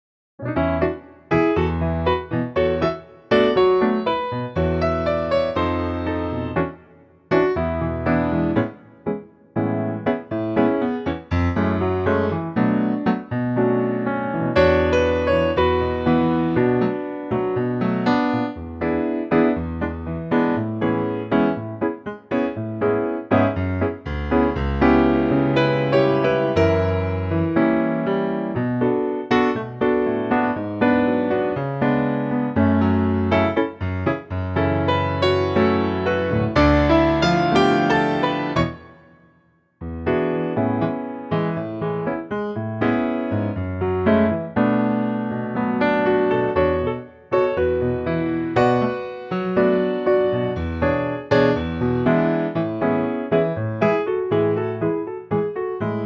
key - A - vocal range - B to E